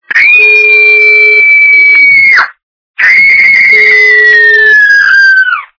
» Звуки » Люди фразы » Бешеная девка - Вопли бешеной девки
Звук Бешеная девка - Вопли бешеной девки